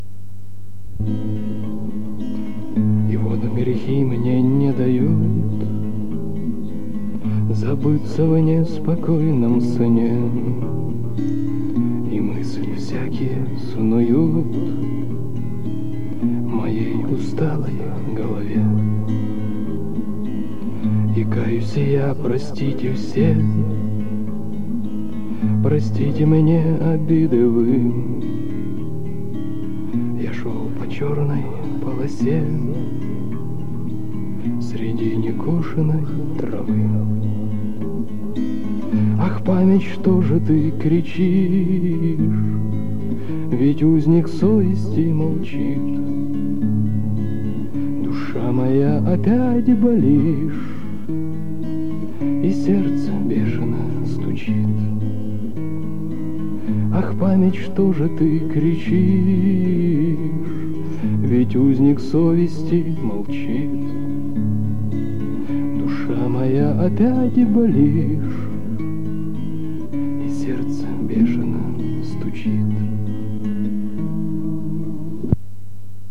Нет сна К сожалению запись частично повреждена, начало утеряно